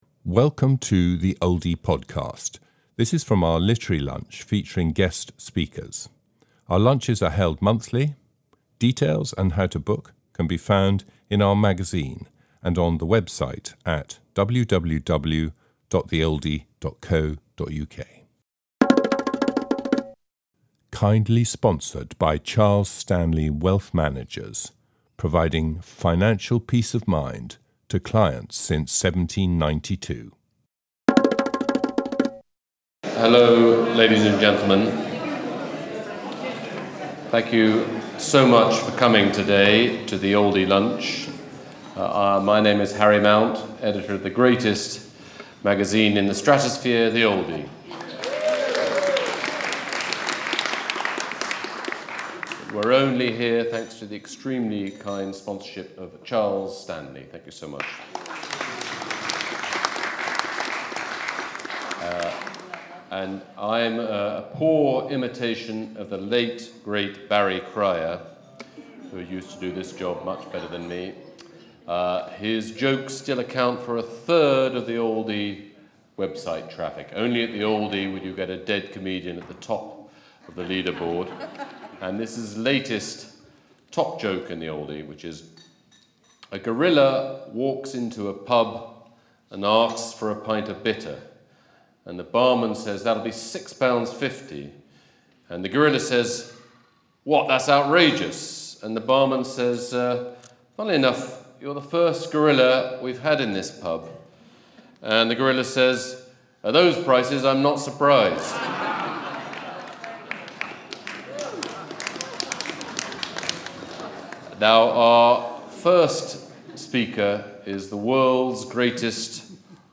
Oldie Literary Lunch Recording